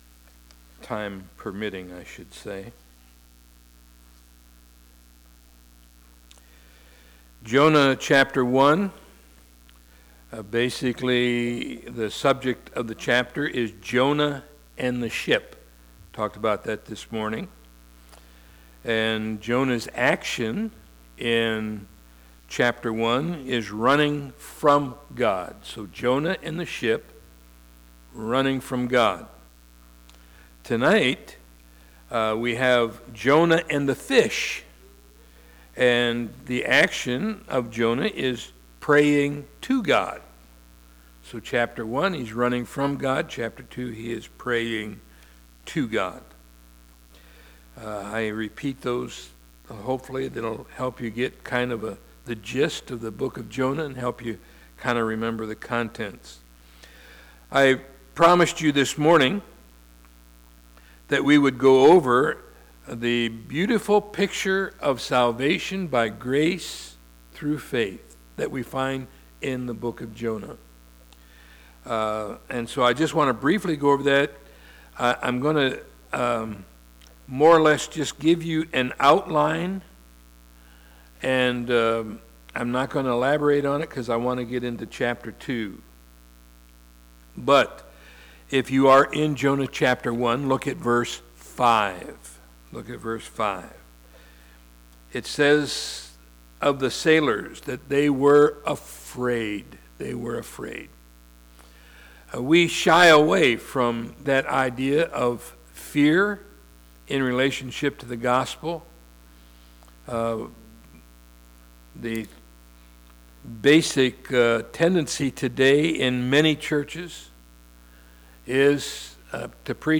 Guest Speaker
Scripture - Jonah 4 When - Sunday Evening Service